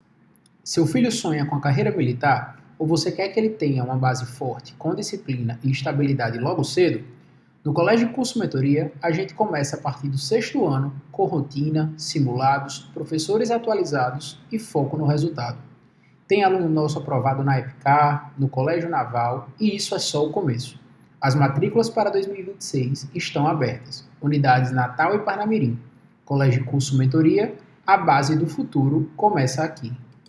Precisamos de uma entonação empolgante, confiante e que traga segurança ao ouvinte.